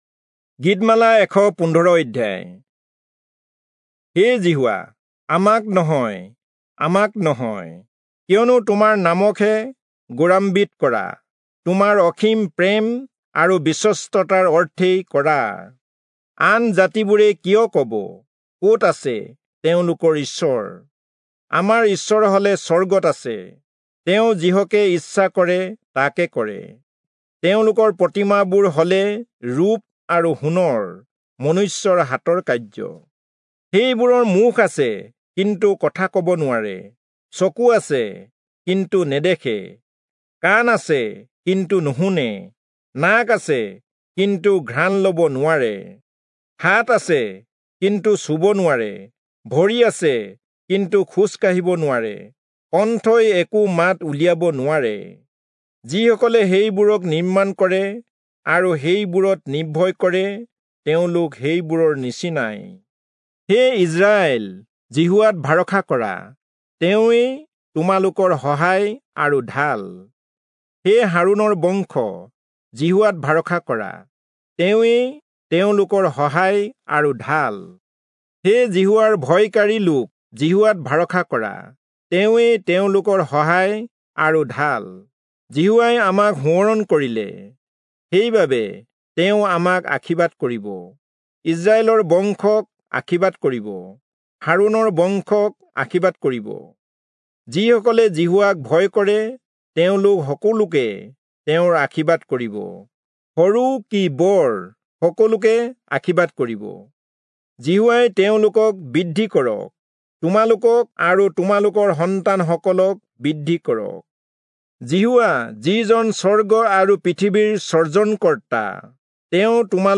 Assamese Audio Bible - Psalms 128 in Lxxrp bible version